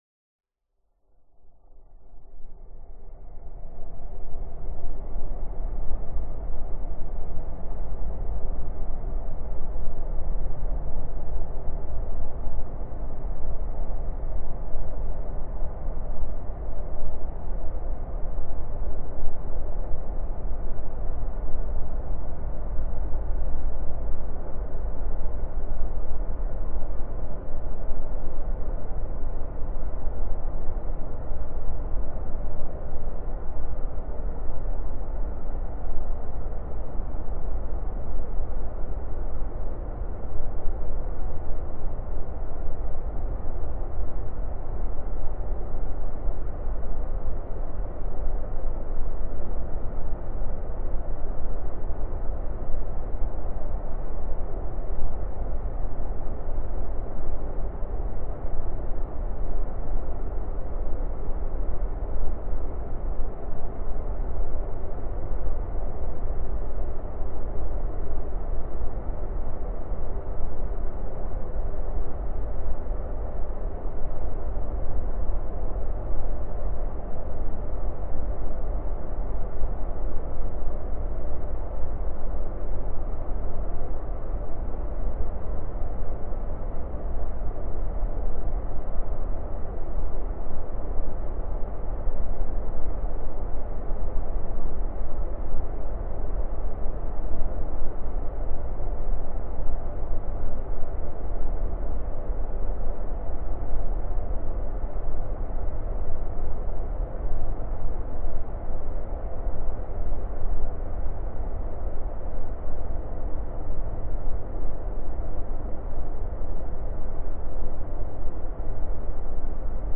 Здесь собраны реалистичные аудиоэффекты: скрип половиц, завывание ветра в пустых комнатах, отдаленные голоса и другие жуткие детали.
Фоновый звук заброшенного пустого здания